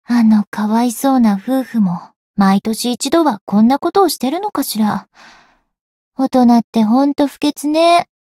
灵魂潮汐-星见亚砂-七夕（摸头语音）.ogg